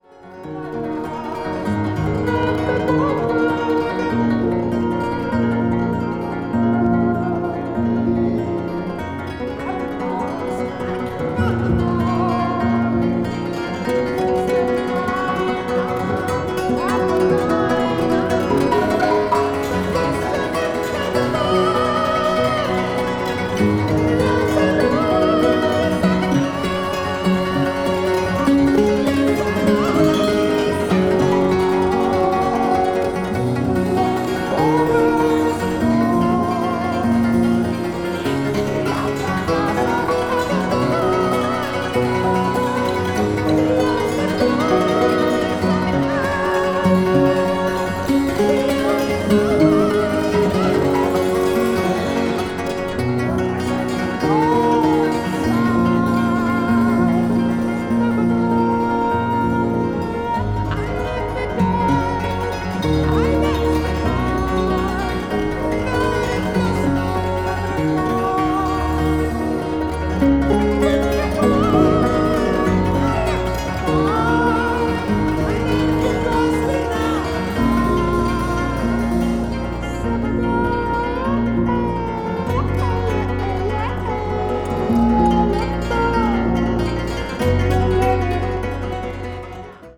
balearic   ethnic jazz   new age   progressive rock